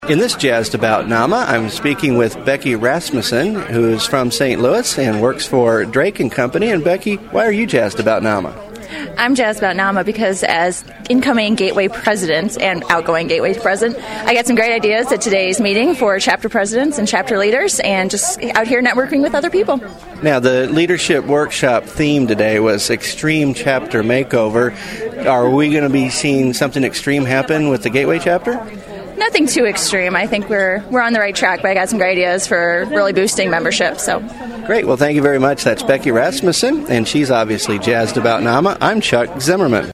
This was recorded with her at the NAMA Conference in KC, MO last week.